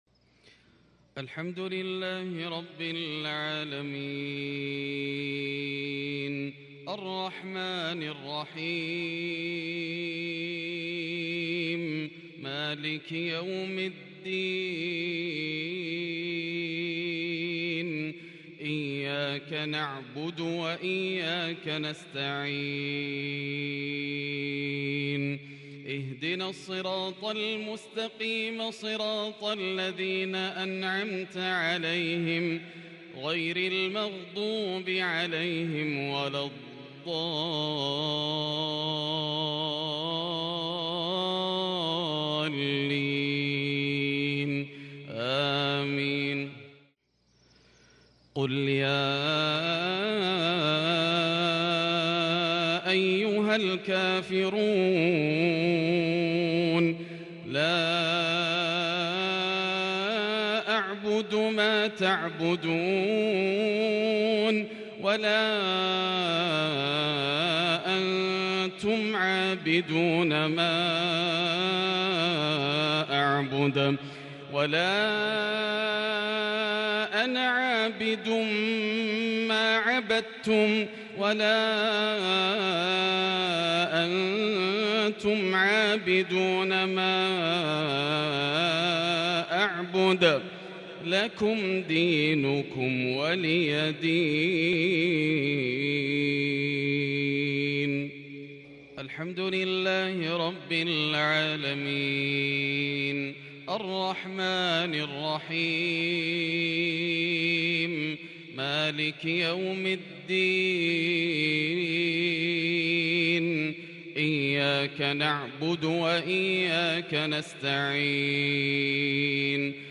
مغرب الأحد 11 شوال 1442هــ سورتي الكافرون و الإخلاص | Maghrib prayer from the Surat al-Kafir and al-Ikhlas 23/5/2021 > 1442 🕋 > الفروض - تلاوات الحرمين